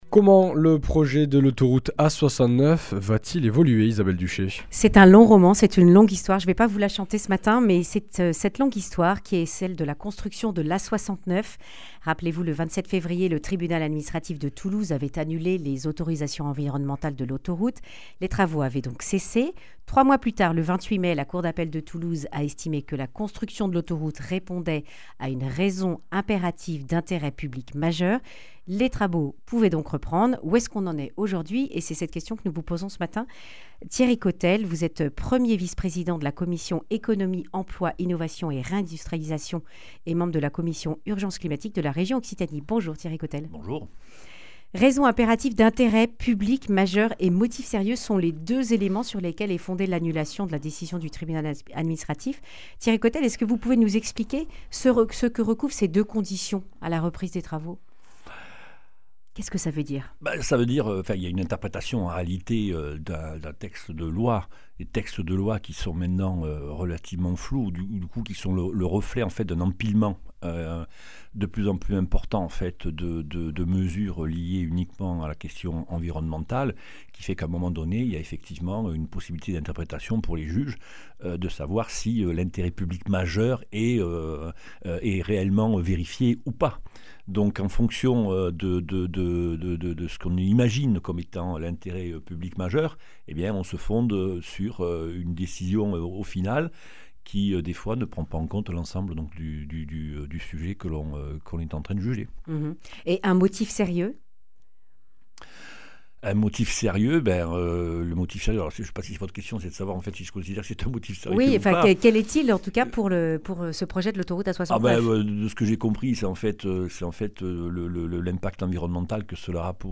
Accueil \ Emissions \ Information \ Régionale \ Le grand entretien \ Autoroute A69, un premier pas vers le désenclavement ?